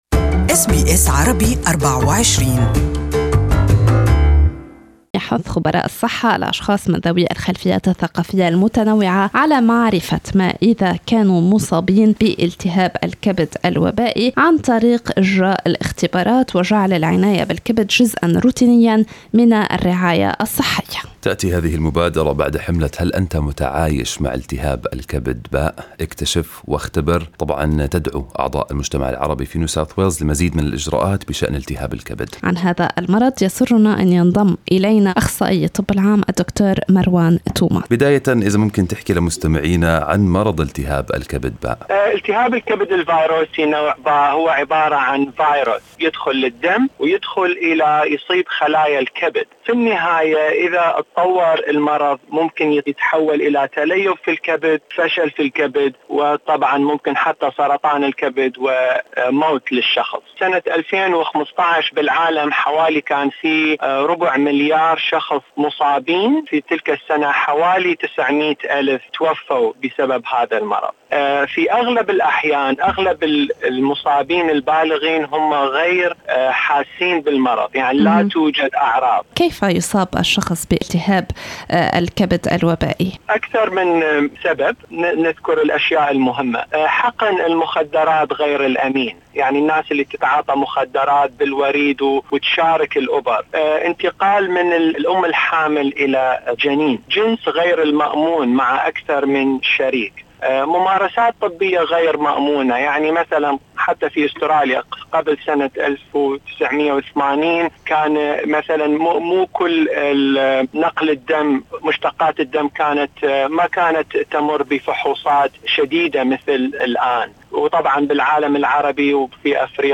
This interview is available in Arabic